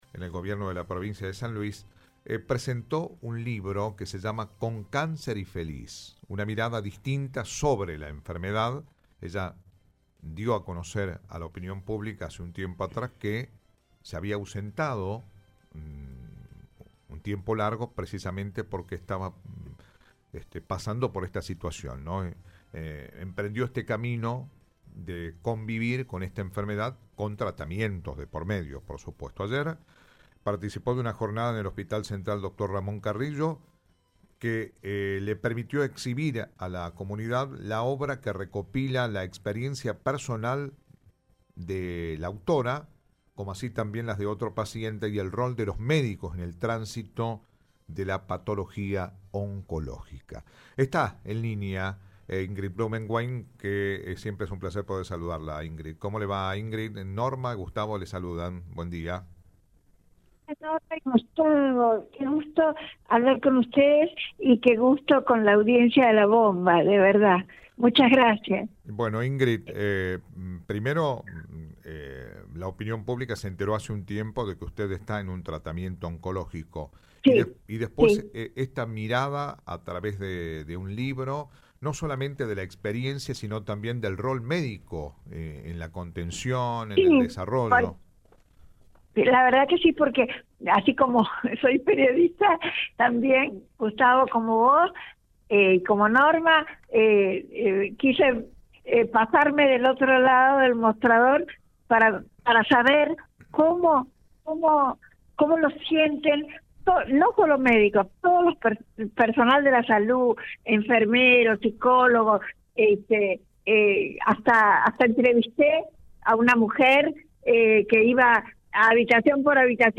En diálogo con Viva la Mañana